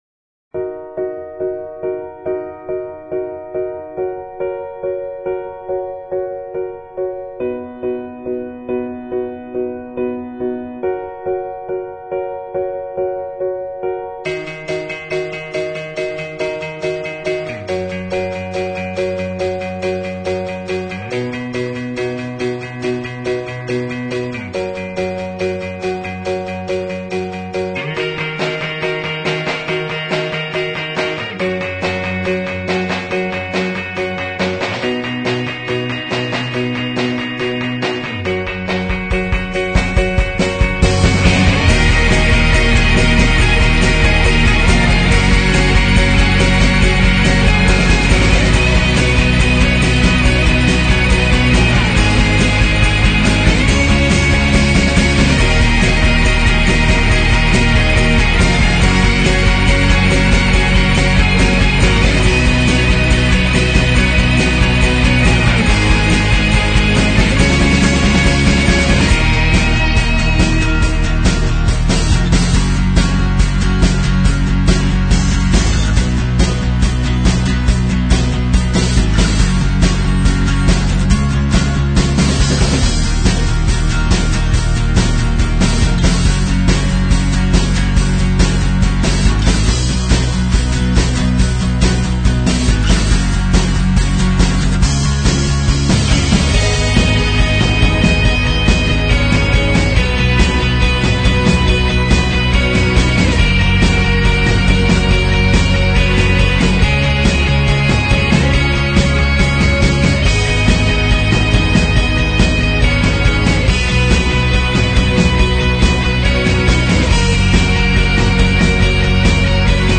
描述：是一个令人兴奋的，激动人心的独立摇滚轨道，唤起感情的自由，冒险，灵感和成功从头到尾